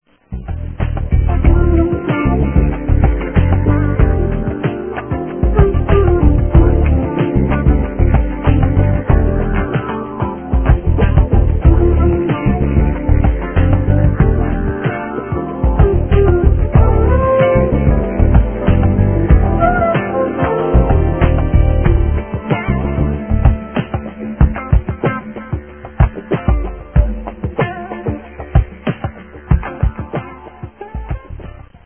New Age
easy, meditative jazz structures
with a funky rhythm, guitar and flute accompaniment